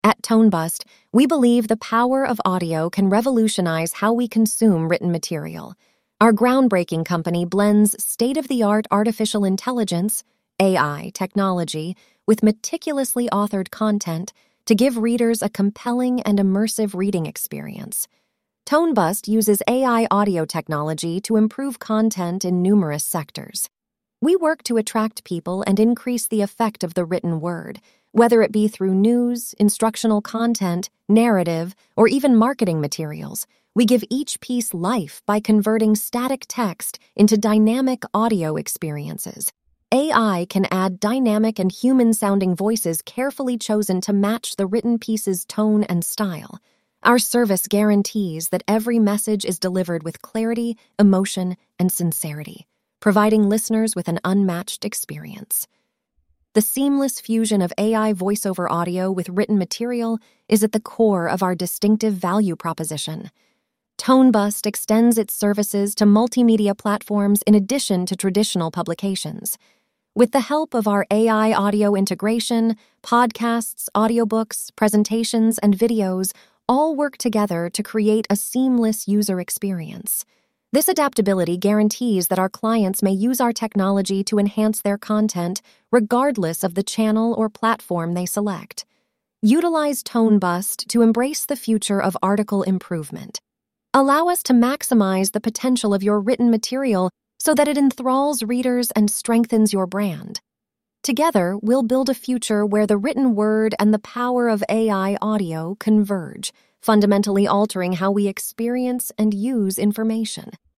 Artificial Intelligence Voiceovers